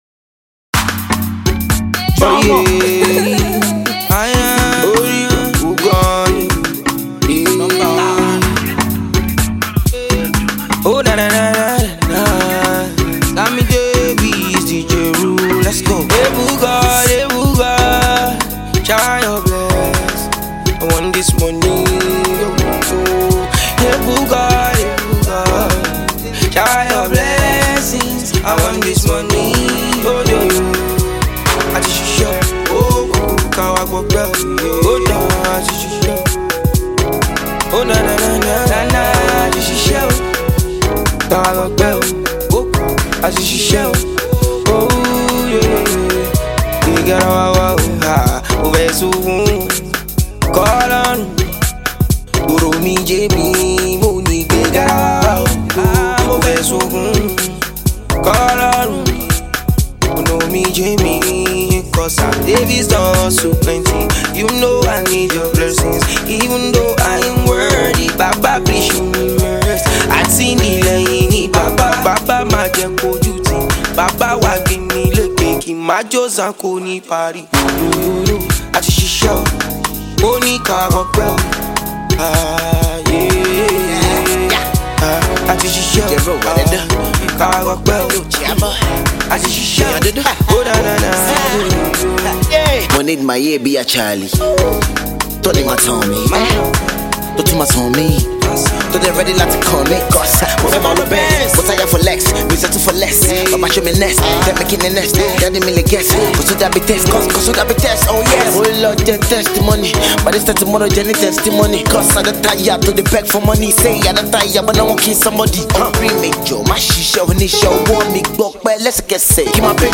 afro-pop singer
a cover